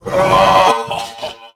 RobosaLaugh.ogg